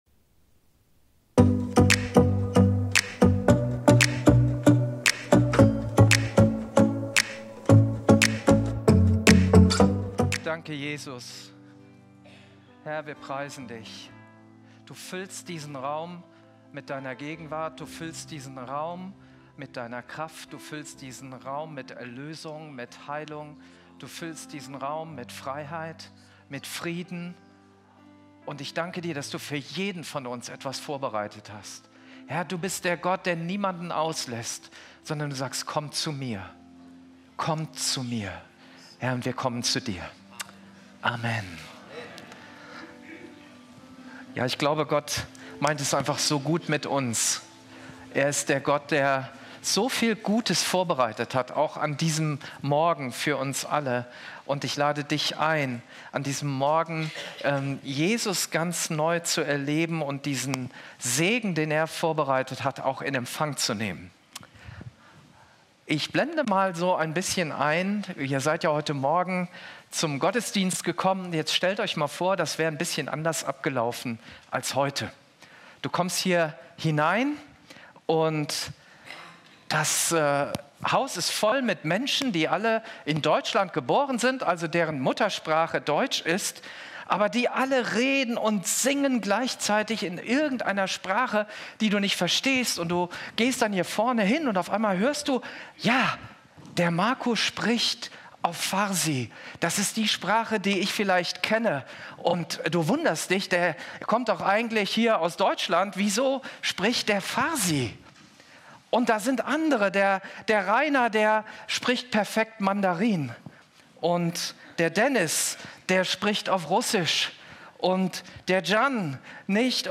Video und MP3 Predigten
Kategorie: Sonntaggottesdienst Predigtserie: Church on fire